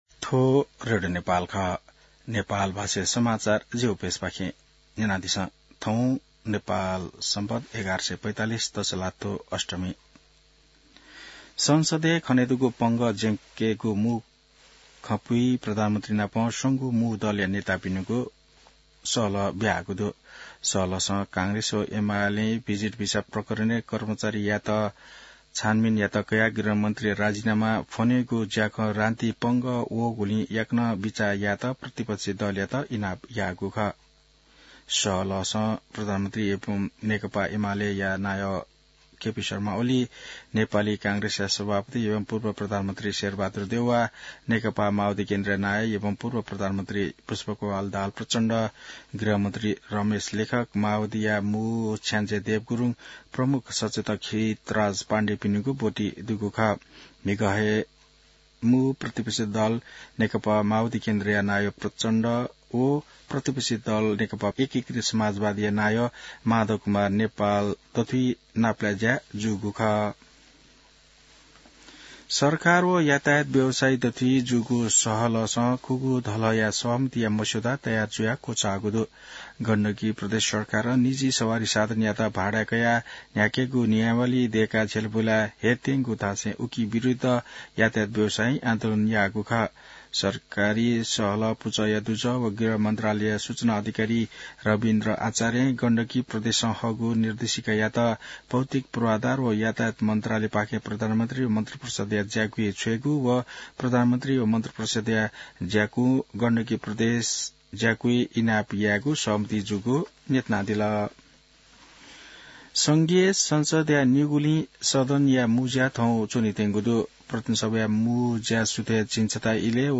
नेपाल भाषामा समाचार : २० जेठ , २०८२